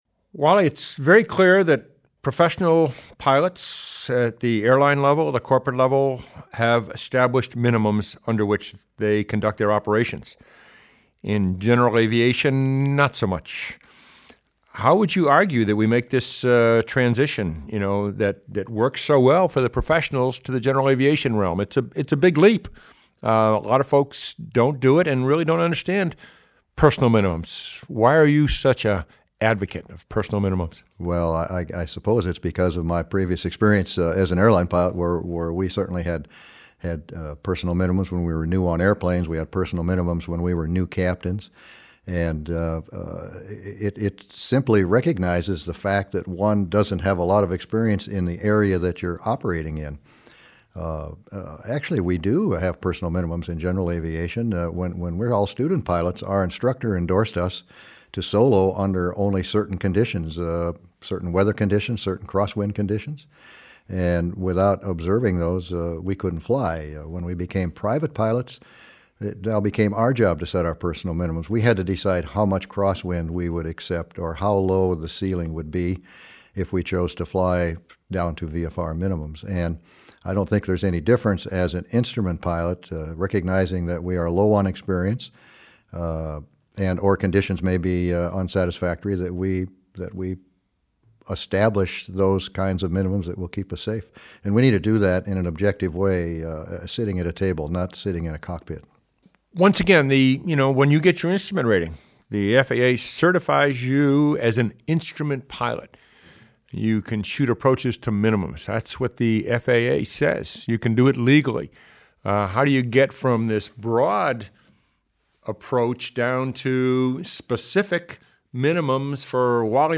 Maintaining-personal-minimums-roundtable.mp3